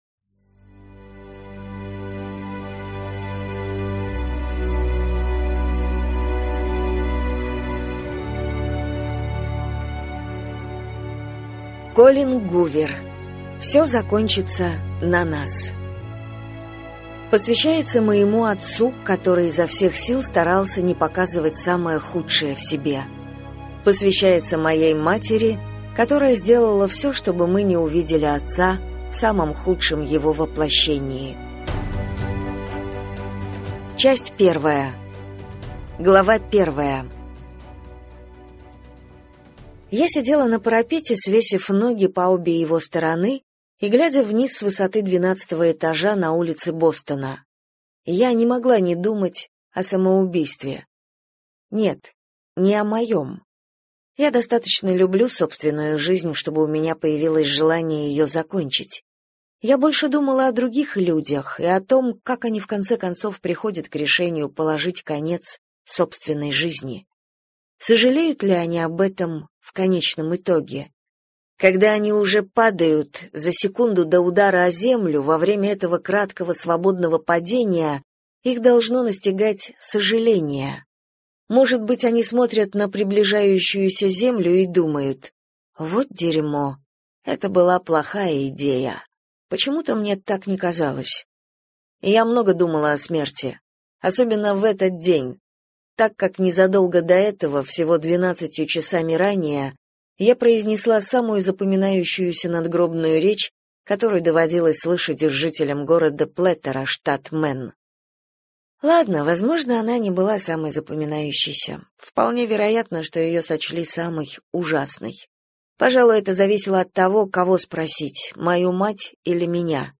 Аудиокнига Все закончится на нас - купить, скачать и слушать онлайн | КнигоПоиск